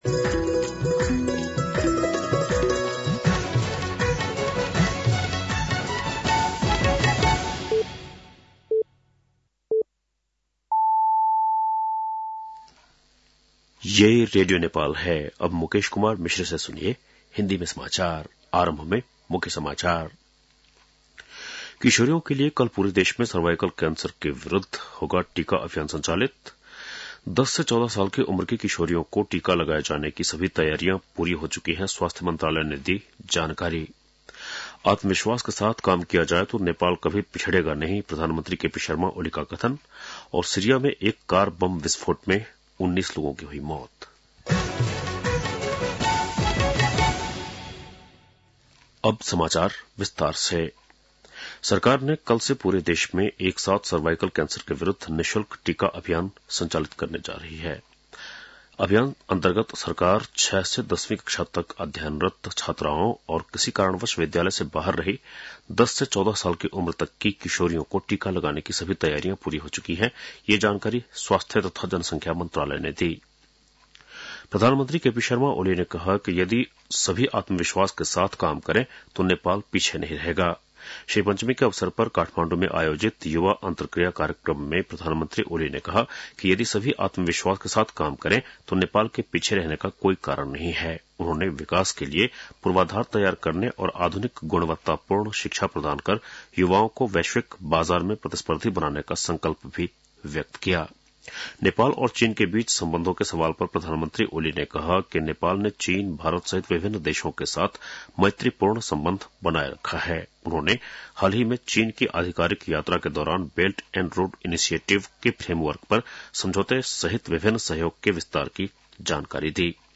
बेलुकी १० बजेको हिन्दी समाचार : २२ माघ , २०८१